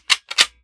7Mag Bolt Action Rifle - Slide Up-Back 001.wav